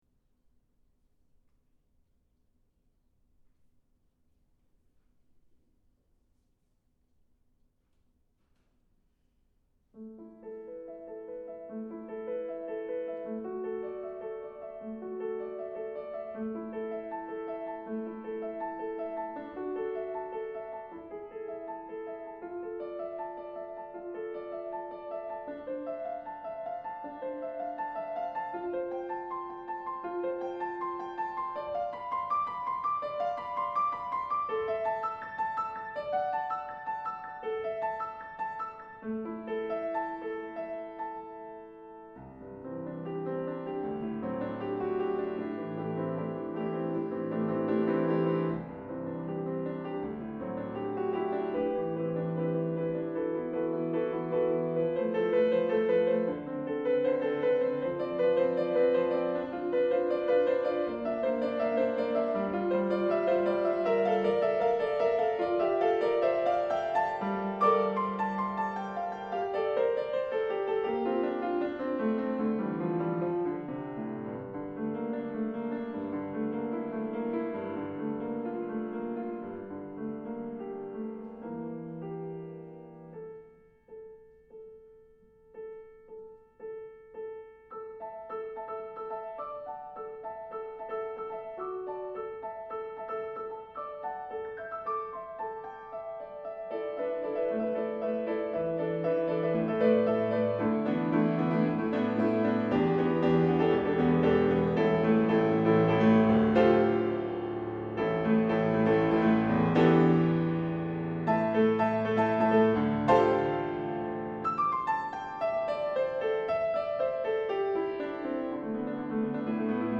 for Piano (2013)